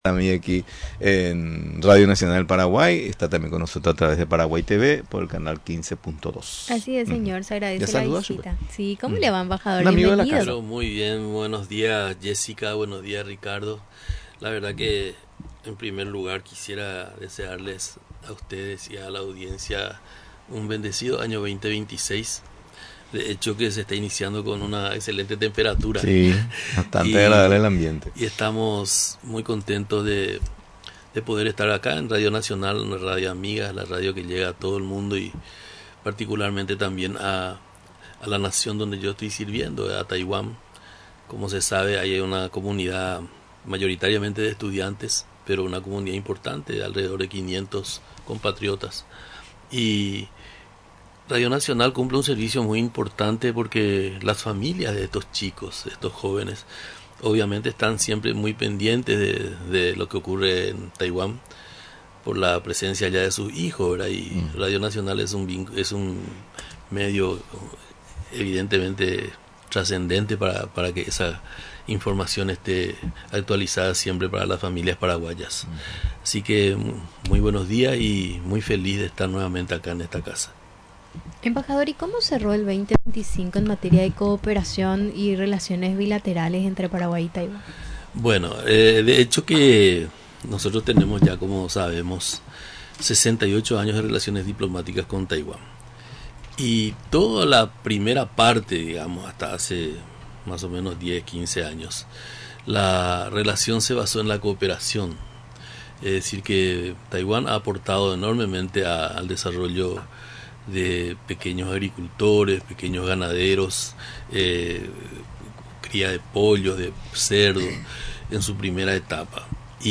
El Embajador de Paraguay ante la República de China (Taiwán), Darío Filártiga, en visita a Radio Nacional, destacó la relevancia de los medios estatales para mantener conectada a la comunidad paraguaya en el exterior, especialmente a los cerca de 500 compatriotas, mayoritariamente estudiantes, que residen en la isla.